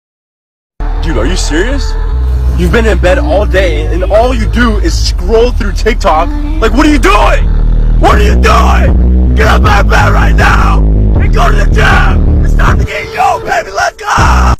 ALARM FOR GYM RATS .mp3